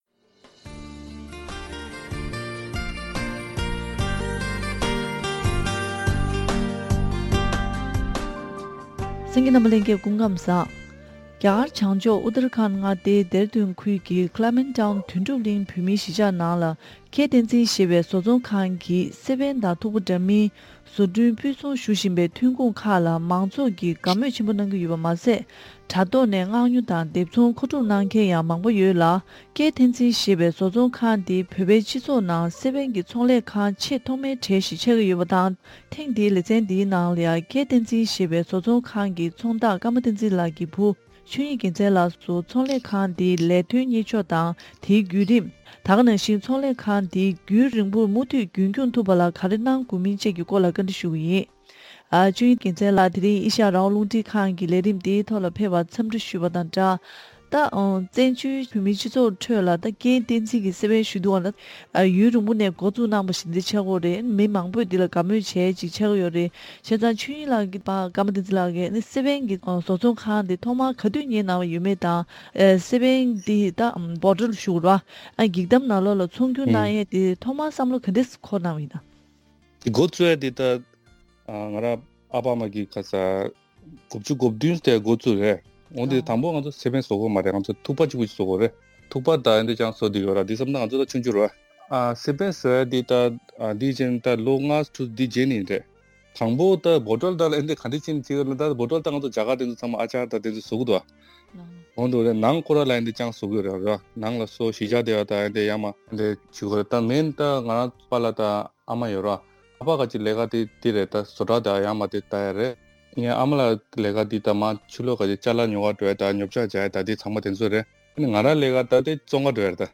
བཀའ་དྲི་ཞུས་པ་ཞིག་གསན་གནང་གི་རེད།